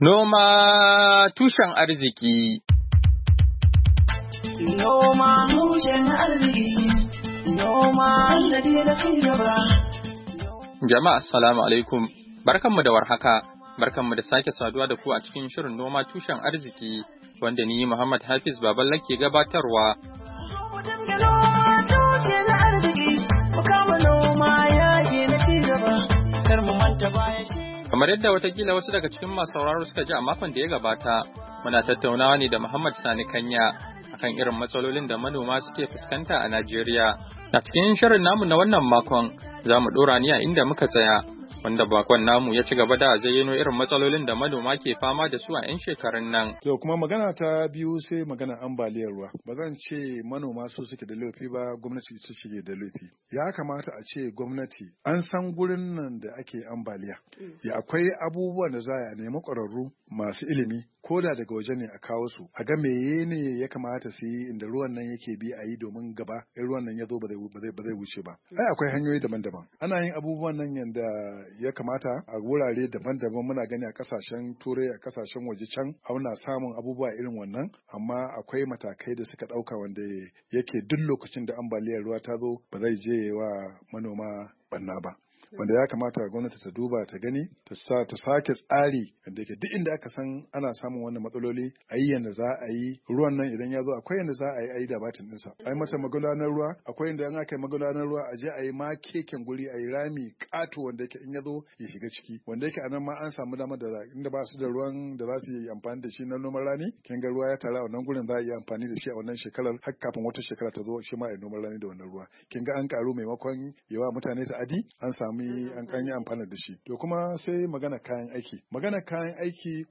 NOMA TUSHEN ARZIKI: Hira Da Manomi Kan Irin Kalubalen Da suke Fuskanta a Najeriya, Kashi na Uku, Oktoba 27, 2020